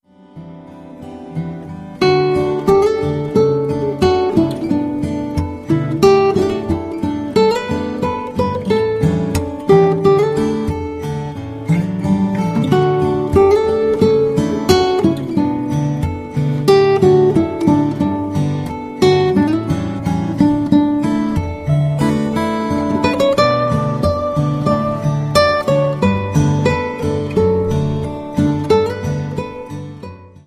Classic Praise Songs on Acoustic Guitars
• Sachgebiet: Praise & Worship